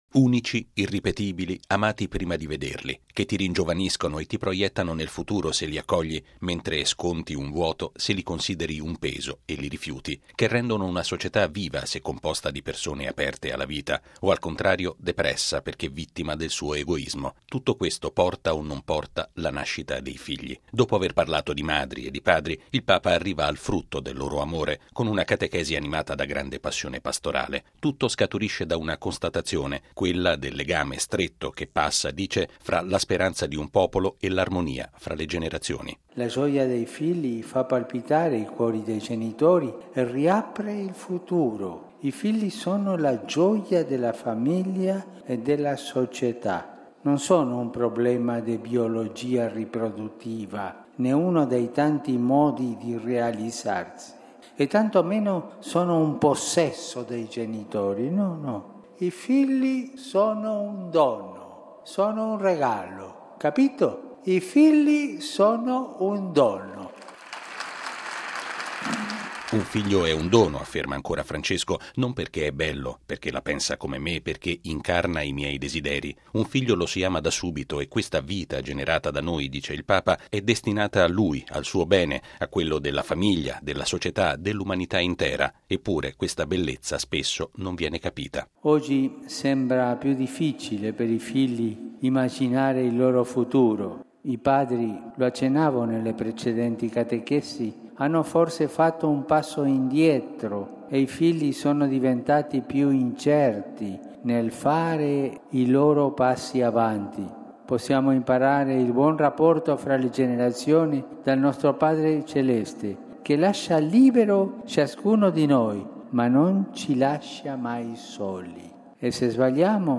È una delle considerazioni centrali della catechesi di Papa Francesco all’udienza generale presieduta in Piazza San Pietro.